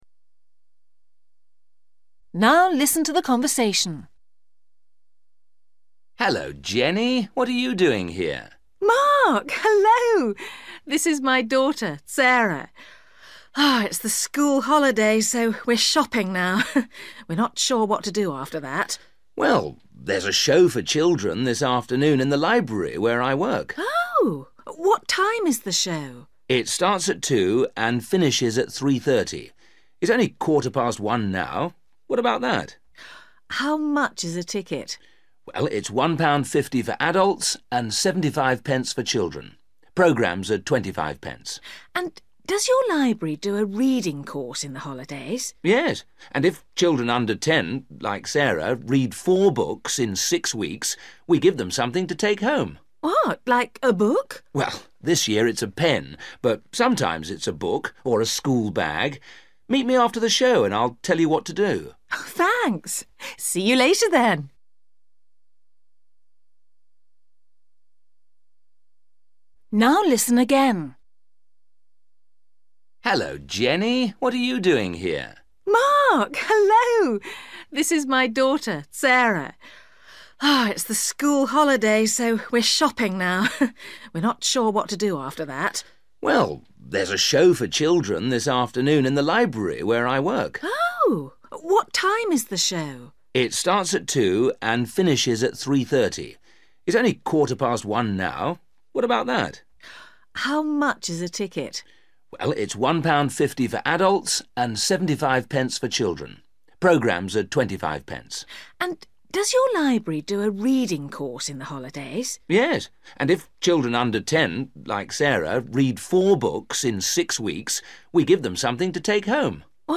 You will hear the conversation twice.